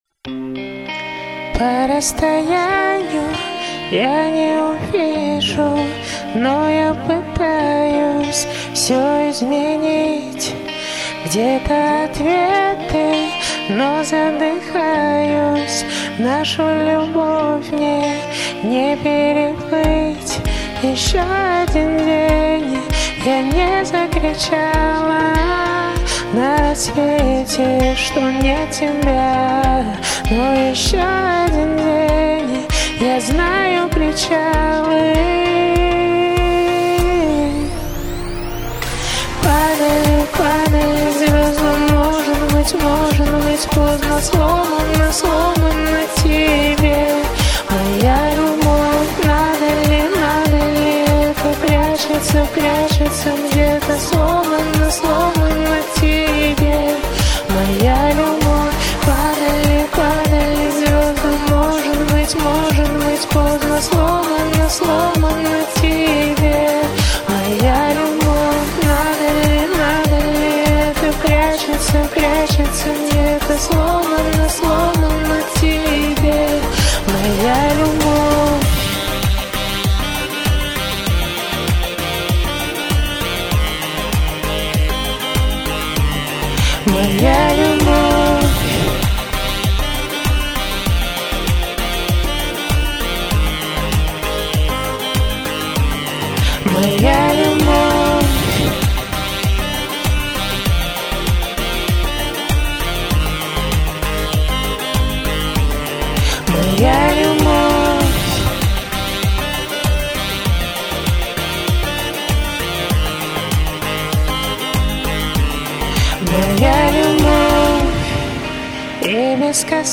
Стильно и чувственно.
очень частые и шумные вдохи в куплетах.